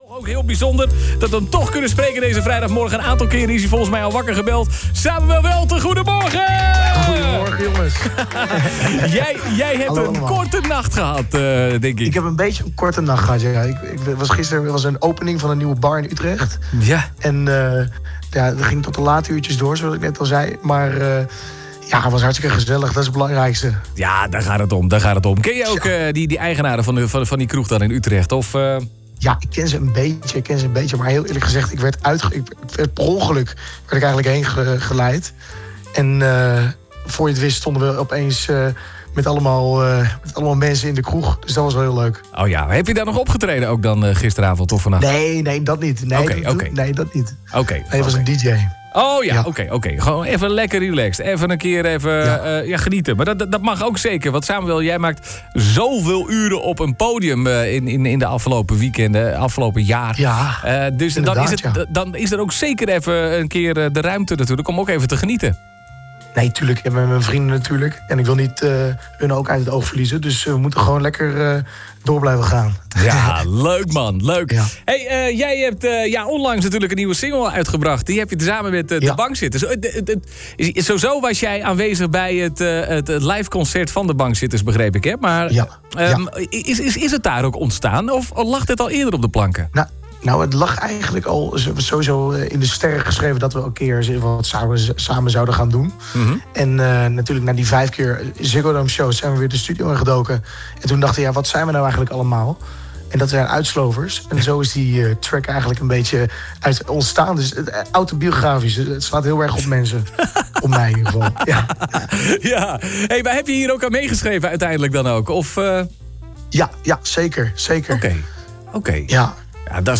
telefonisch interview